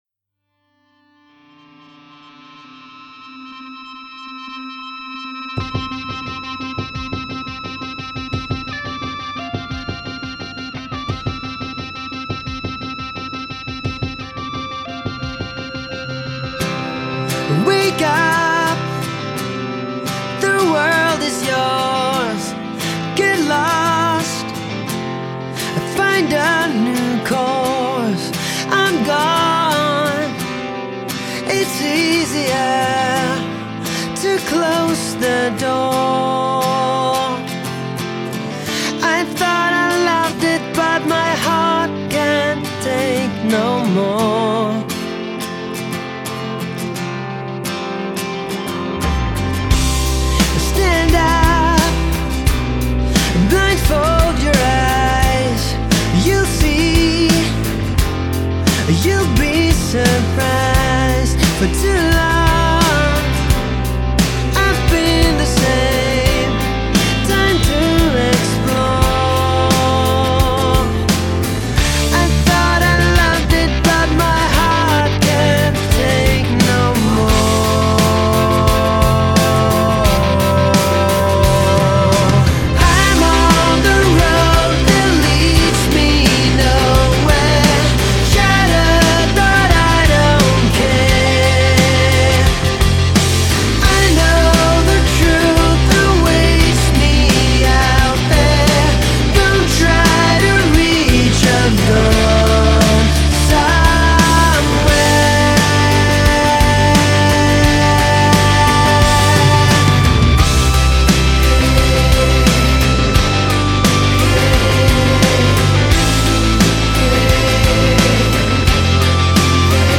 Alternative Rock